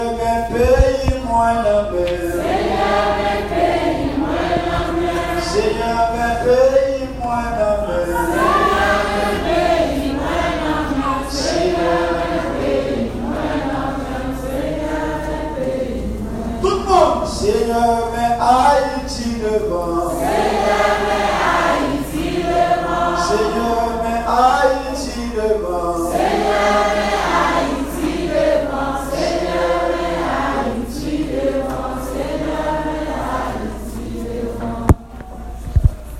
church-of-God-song.m4a